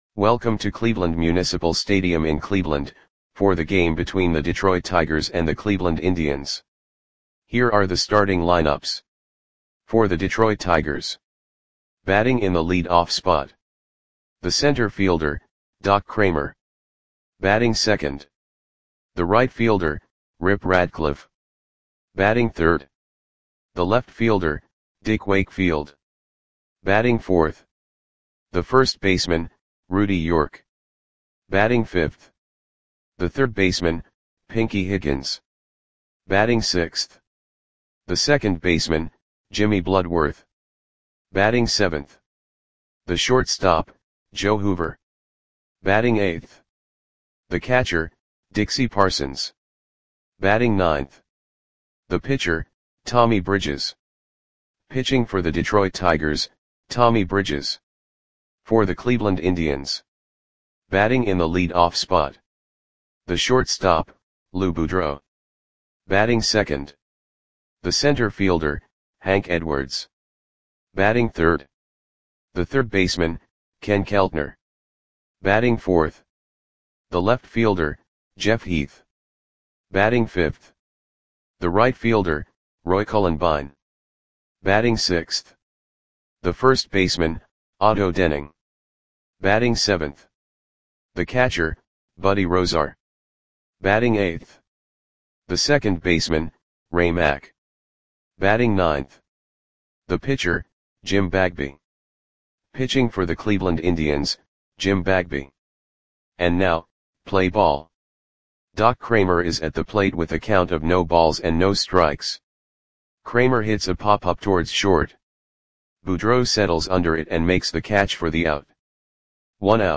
Audio Play-by-Play for Cleveland Indians on April 21, 1943
Click the button below to listen to the audio play-by-play.